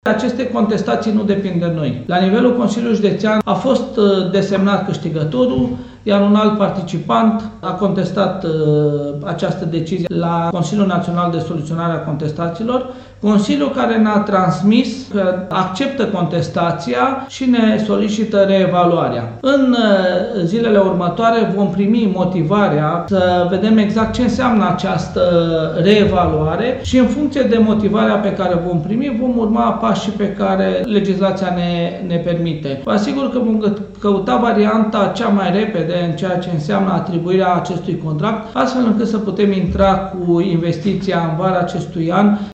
Presedintele Consiliului Judetean Timis, Calin Dobra,spune că așteaptă motivarea deciziei, iar în funcție de aceasta, administrația va căuta cea mai rapidă variantă pentru atribuirea acestui contract: